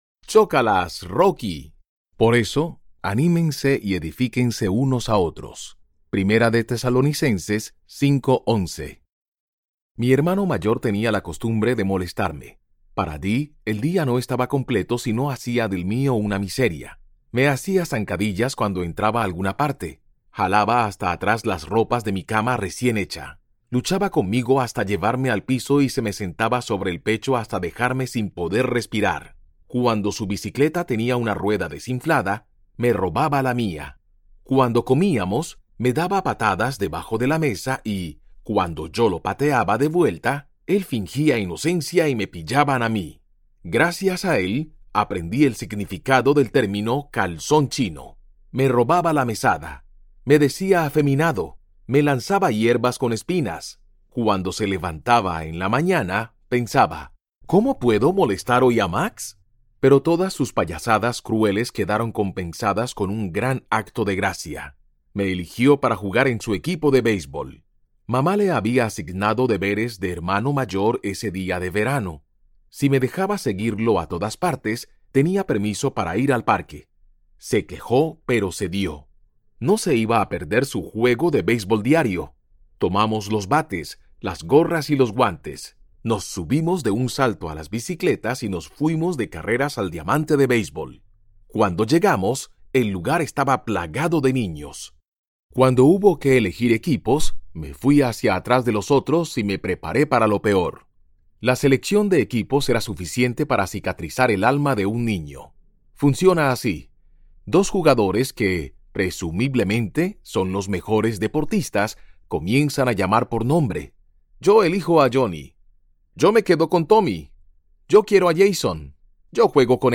El secreto de la felicidad Audiobook